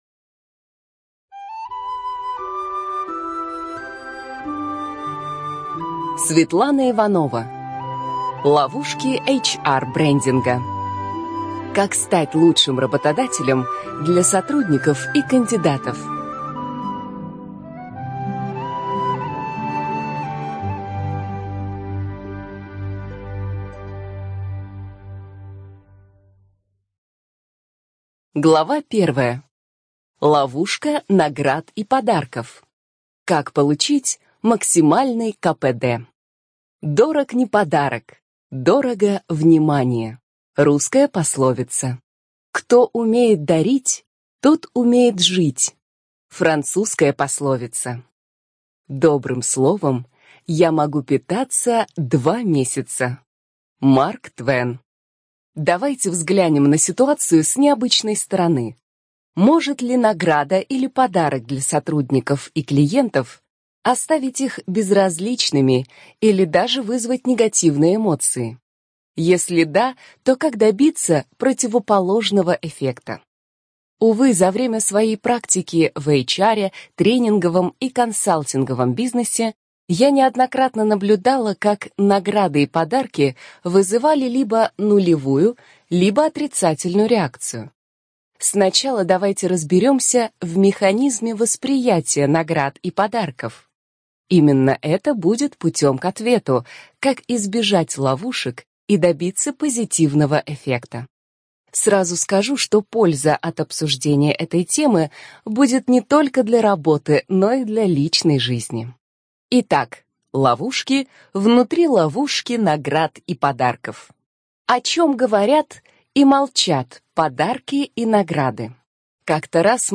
ЖанрДеловая литература